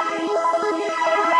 SaS_MovingPad03_170-E.wav